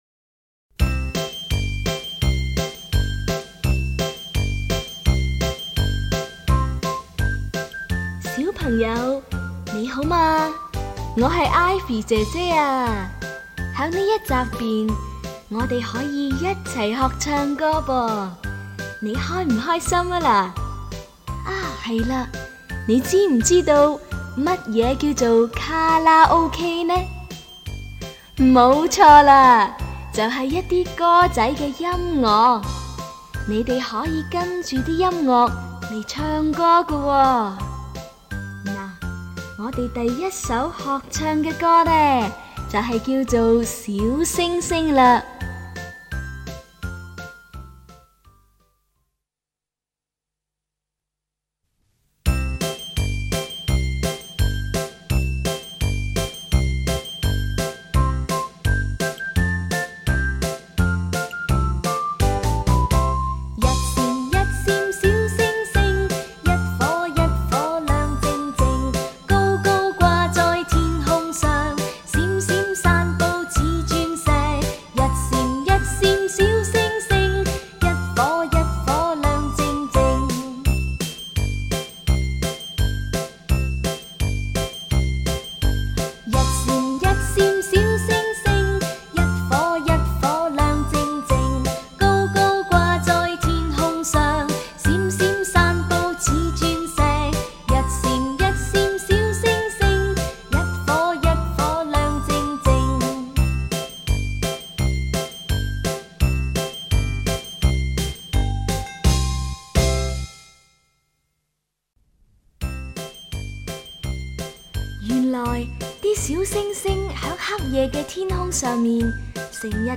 ［示唱+伴唱］